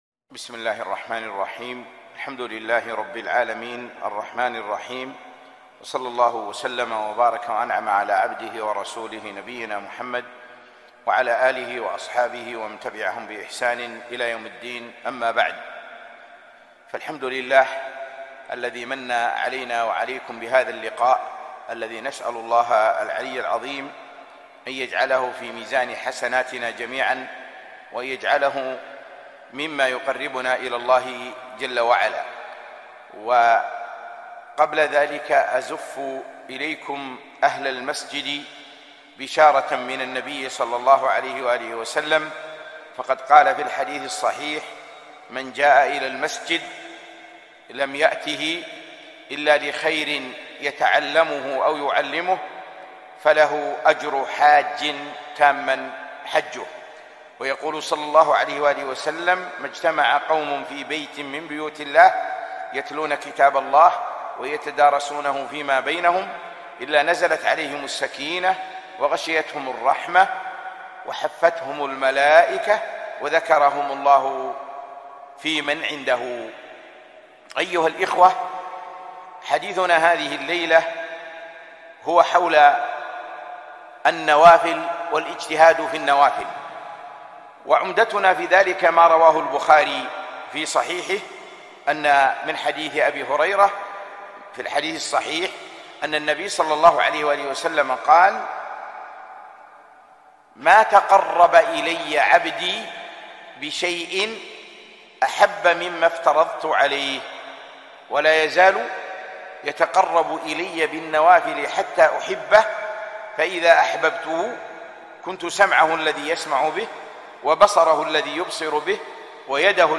محاضرة - التقرب الى الله بالنوافل